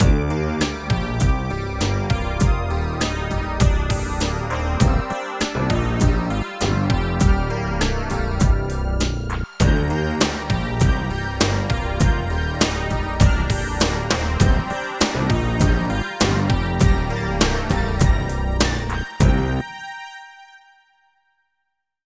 12 LOOP B -R.wav